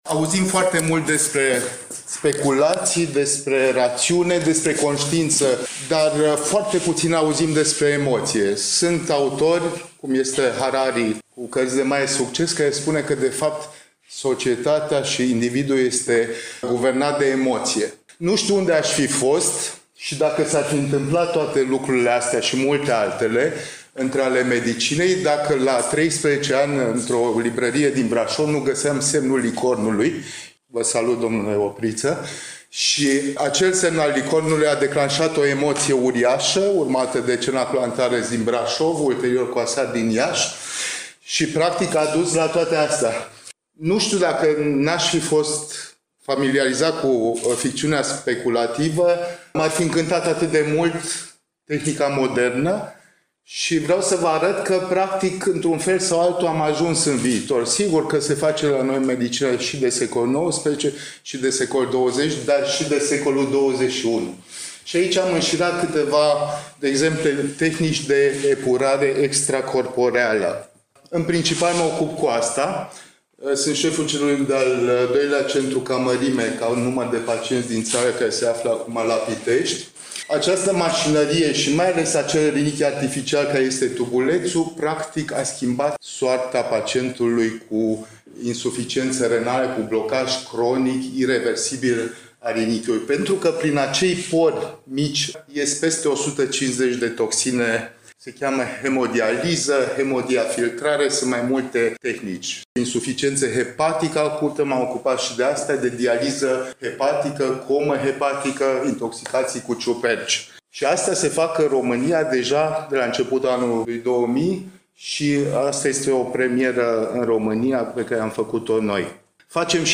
Îl ascultăm aici cu un fragment din prezentarea sa ținută nu la un congres sau simpozion medical, ci la Convenția Națională de Science Fiction, organizată de ARSFAN, la Timișoara, in 2019…